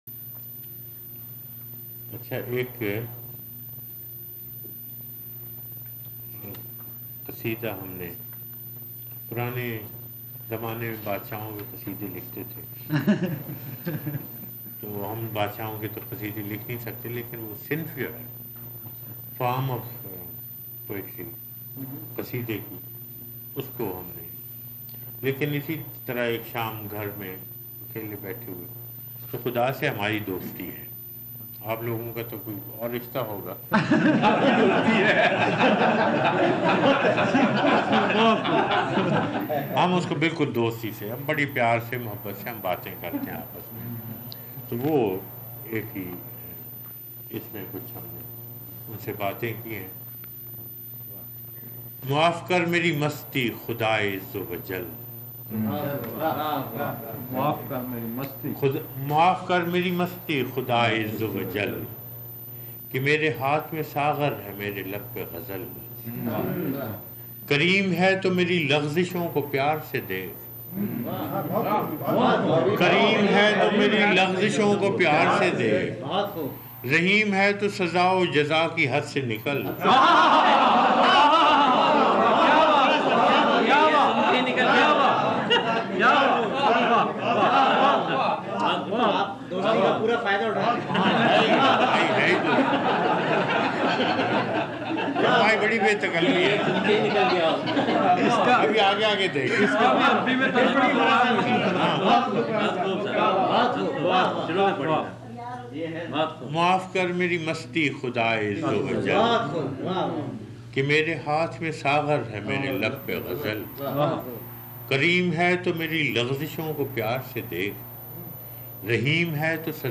It is posted on youtube as a recitation by faraz at a private gathering.